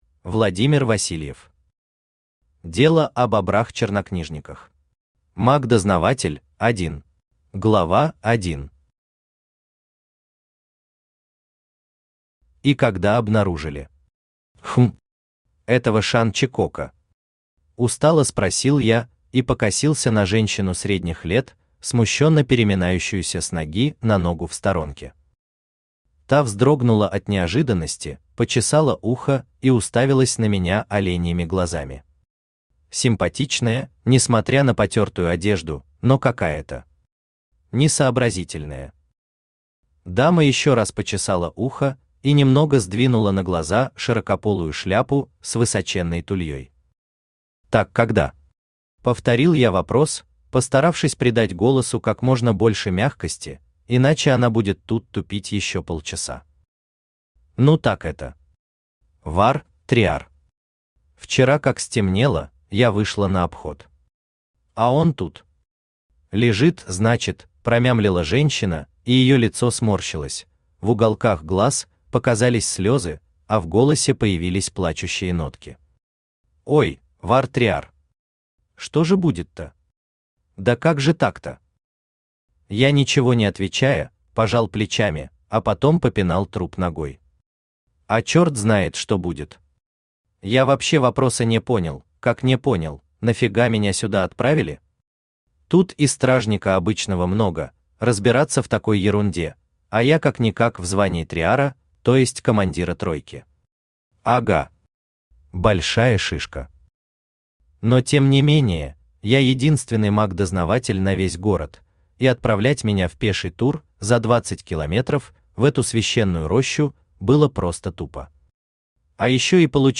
Аудиокнига Дело о бобрах-чернокнижниках. Маг-дознаватель – 1 | Библиотека аудиокниг
Маг-дознаватель – 1 Автор Владимир Васильев Читает аудиокнигу Авточтец ЛитРес.